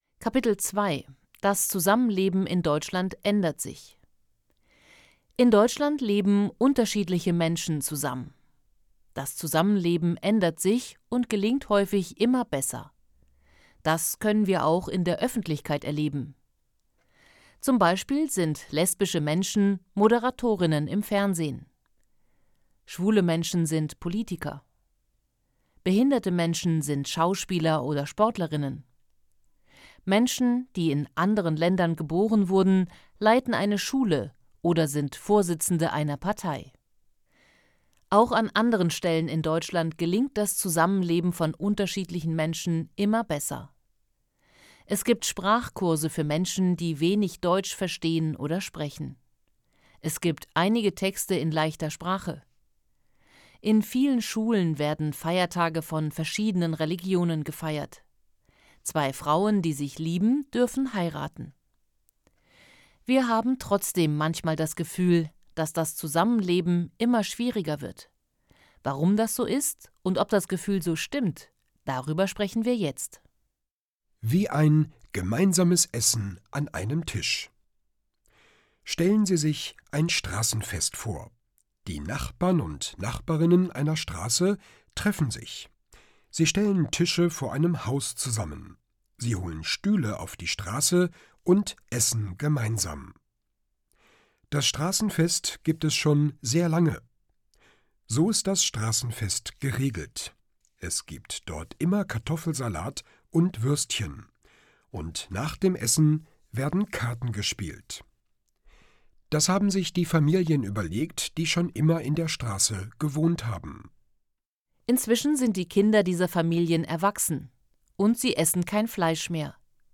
Kapitel 2: Das Zusammenleben in Deutschland ändert sich Hörbuch: „einfach POLITIK: Zusammenleben und Diskriminierung“
• Produktion: Studio Hannover